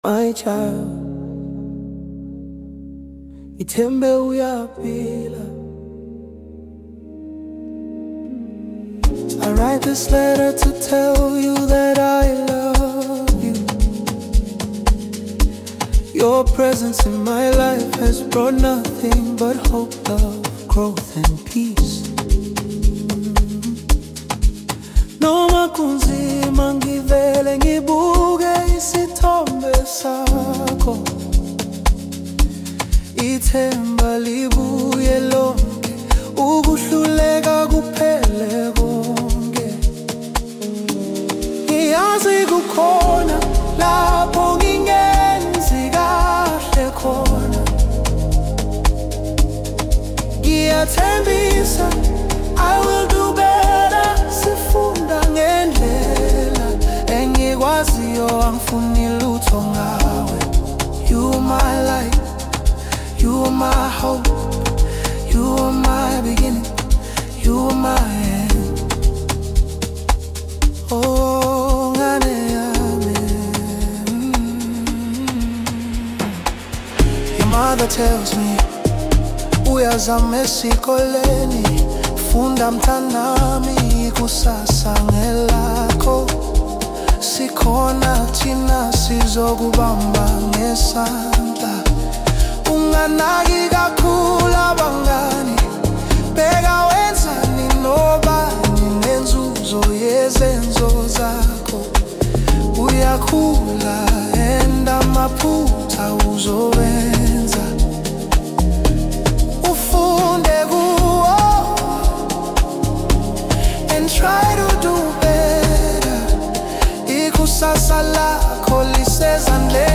Home » Amapiano
South African singer